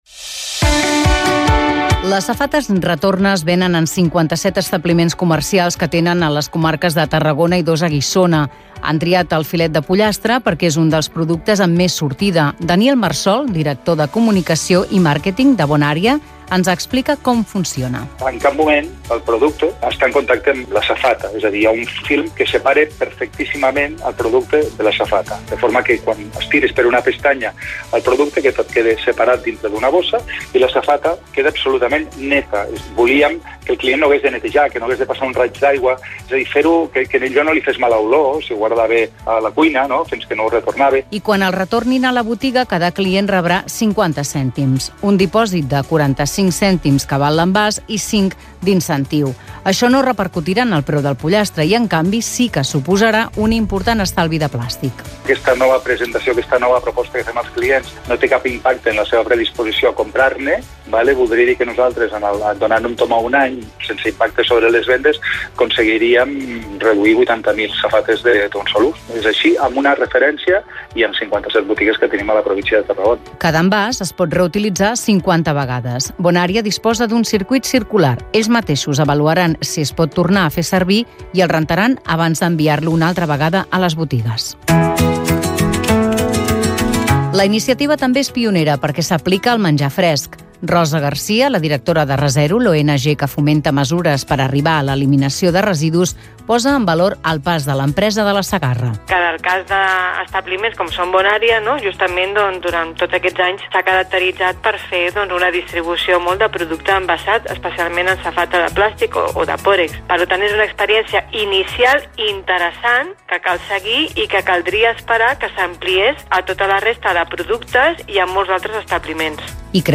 Crònica envasos retornables - Cat Info, 2025